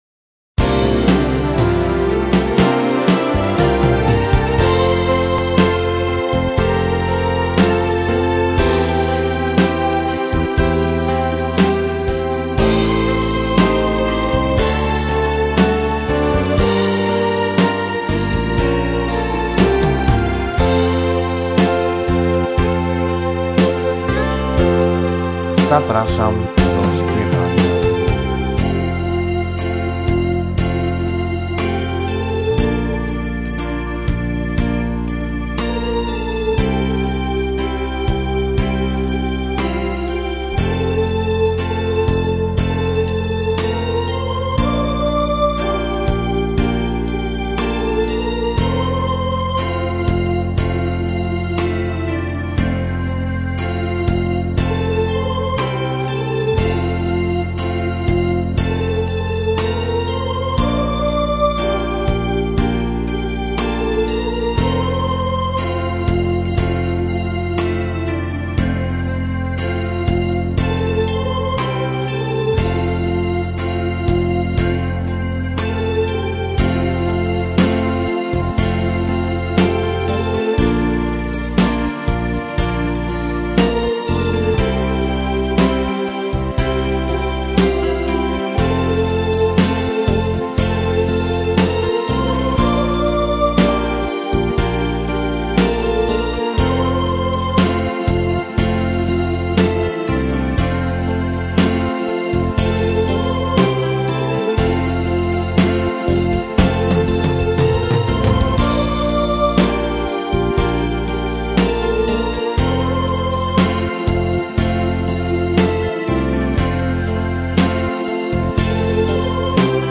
Wersja z linią melodyczną